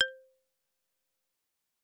content/hifi-public/sounds/Xylophone/C3.L.wav at main
C3.L.wav